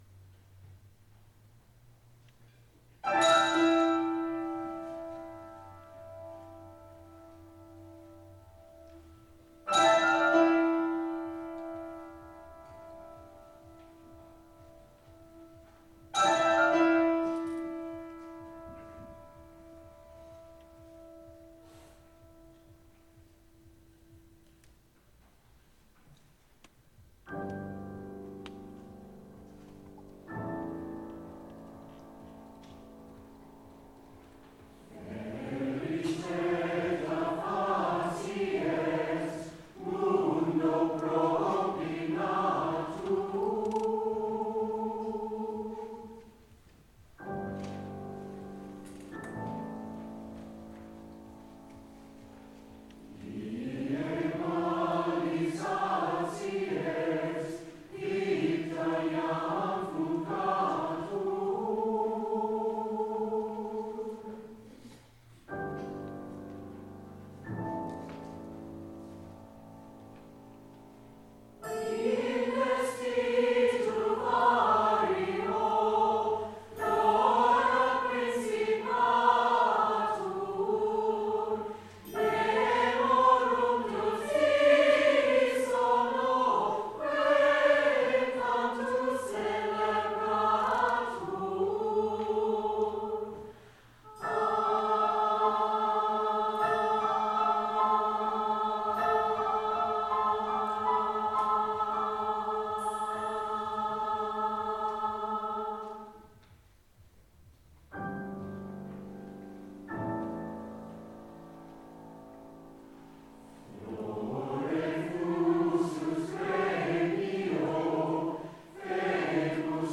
our May 4th concert
Veris laeta facies   Coro